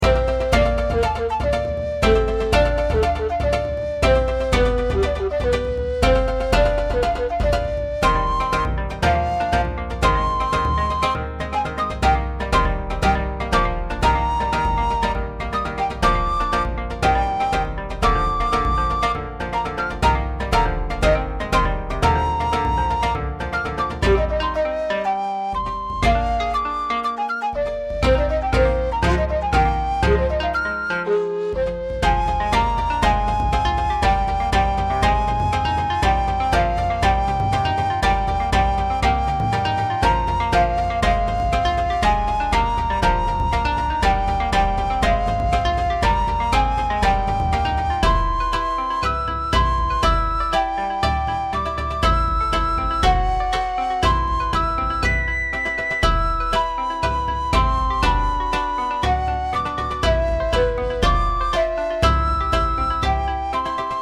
尺八、三味線、琴、太鼓 ※和楽器
BGM